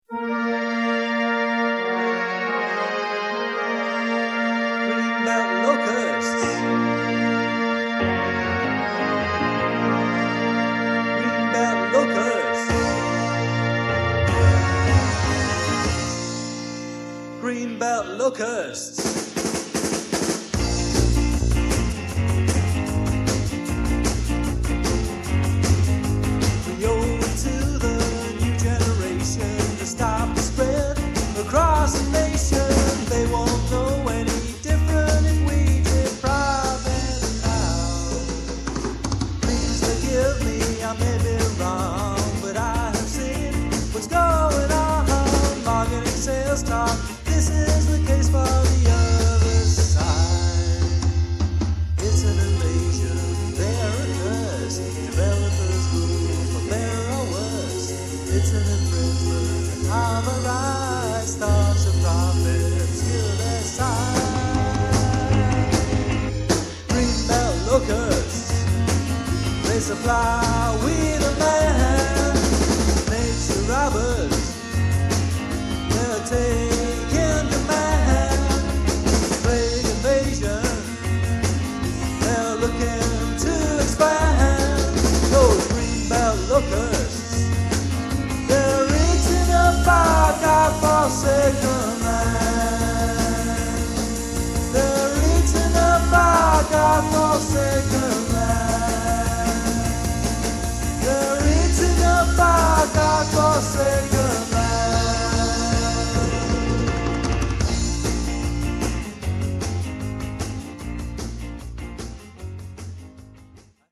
Guitar, vocals
Keyboards, vocals
Drums, vocals